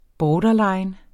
Udtale [ ˈbɒːdʌˌlɑjn ]